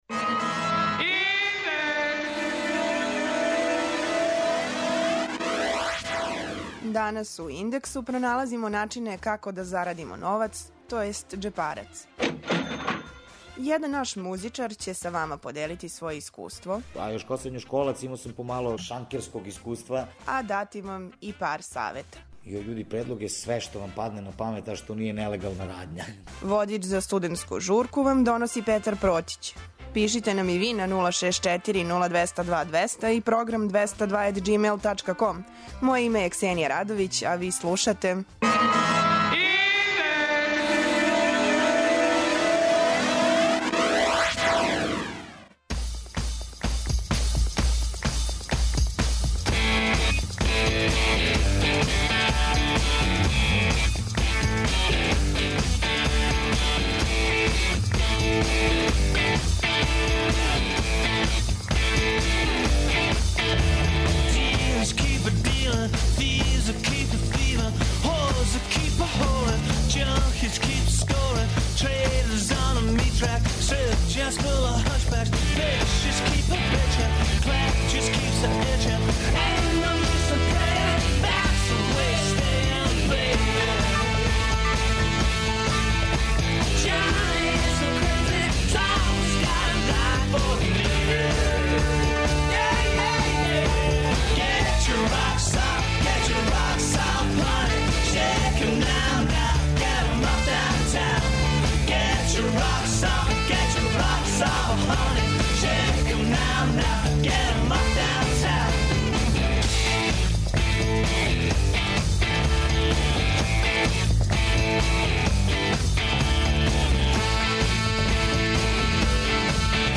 Говорићемо о студентским стипендијама и кредитима, Сајму стипендија, омладинској задрузи. Чућете искуство и предлоге једног нашег музичара.
преузми : 18.41 MB Индекс Autor: Београд 202 ''Индекс'' је динамична студентска емисија коју реализују најмлађи новинари Двестадвојке.